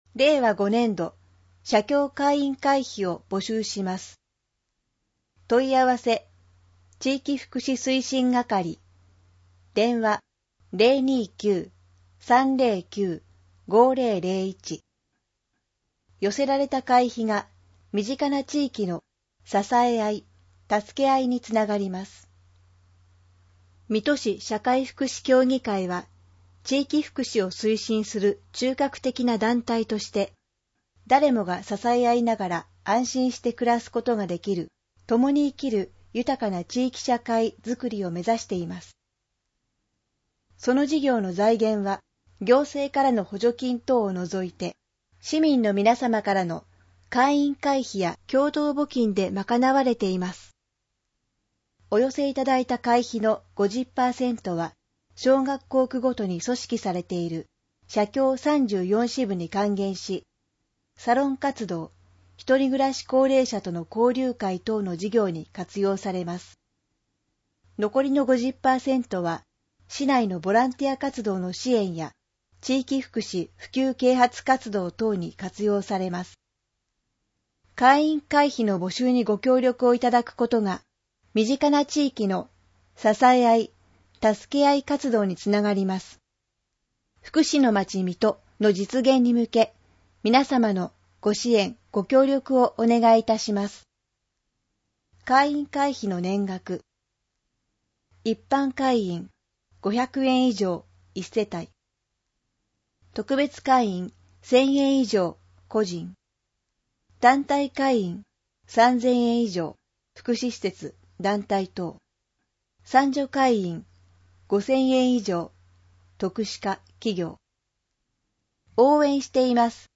音声ガイド
（音声データ作成：音訳ボランティア「こだま」）